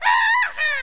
Simon the Killer Ewok's war cry